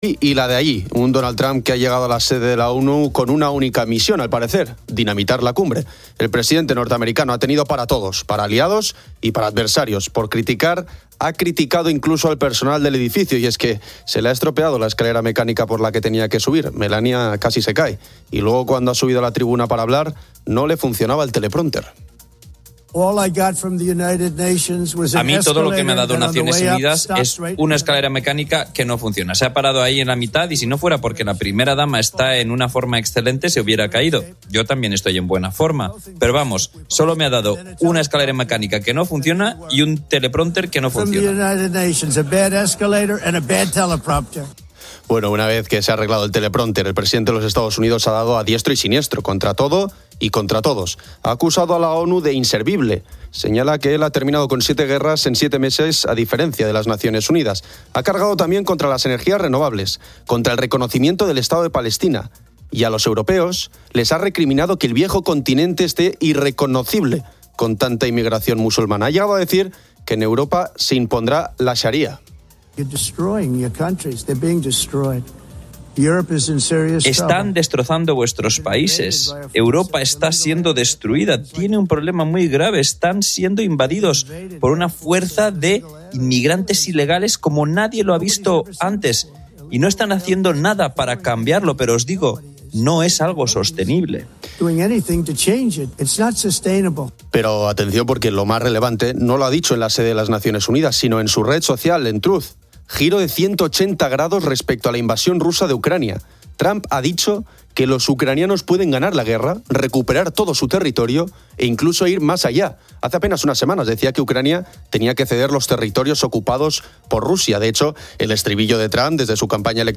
The weather forecast indicates strong storms in Catalonia and the Balearic Islands. Listeners debate topics like children's mobile phone use and online safety.